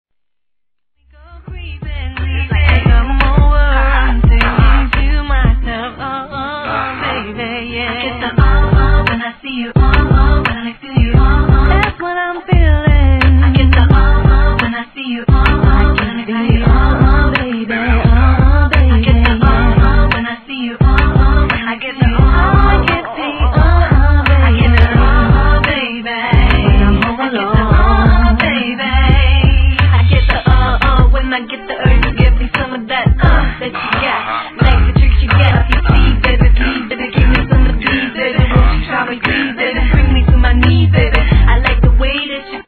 HIP HOP/R&B